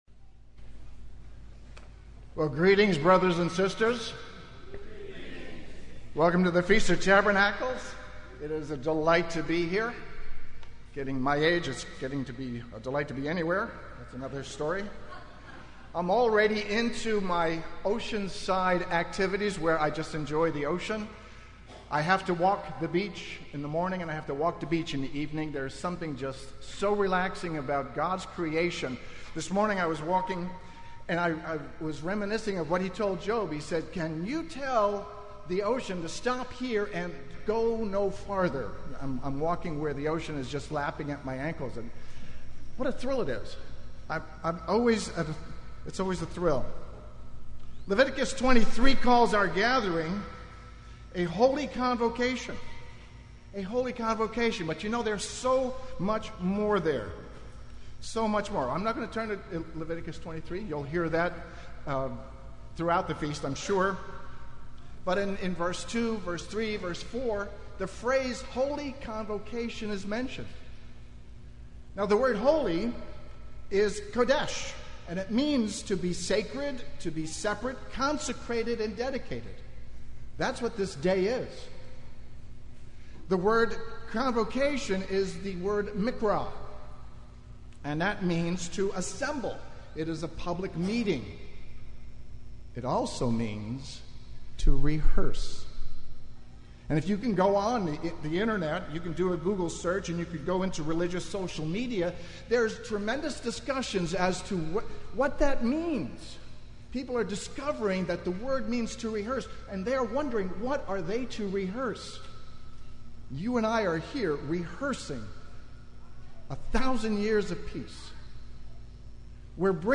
This sermon was given at the Oceanside, California 2018 Feast site.